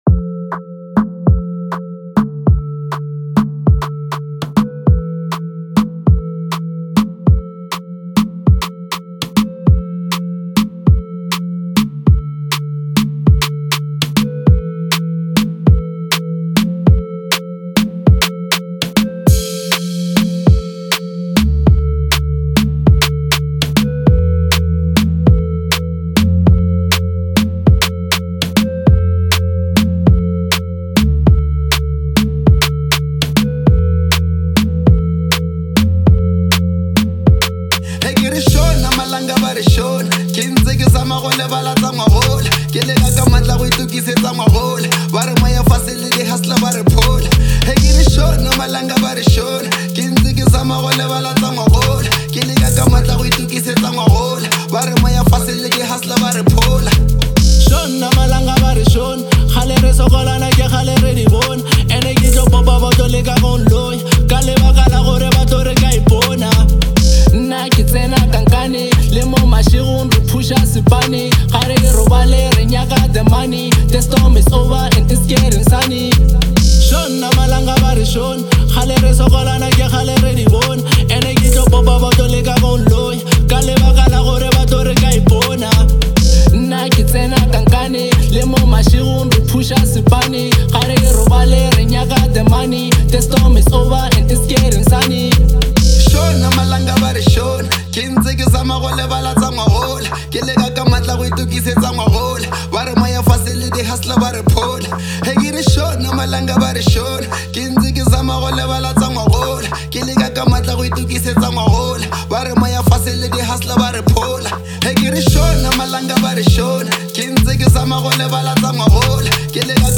deeply moving song